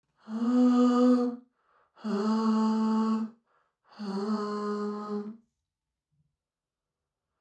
Kurzanhaltende Delays nennen sich auch „Slap-Delays“.
SLAP-DELAY:
fl-studio-vocals-slap-delay.mp3